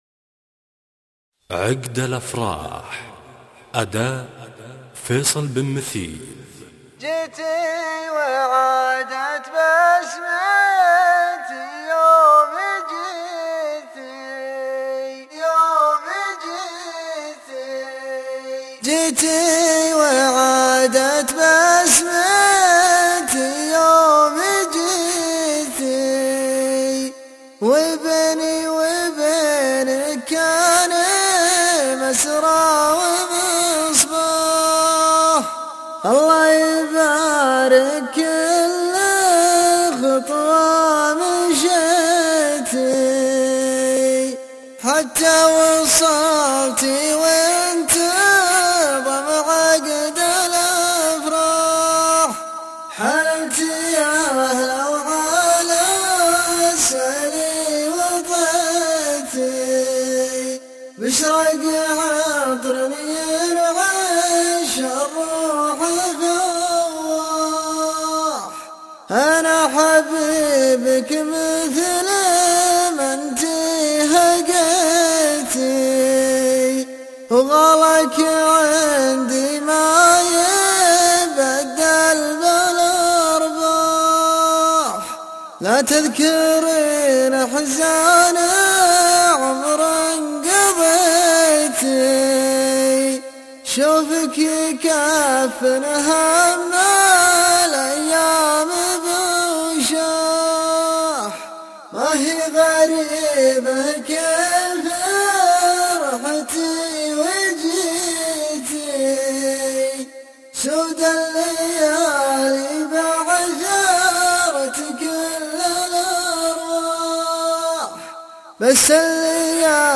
:: الشيلات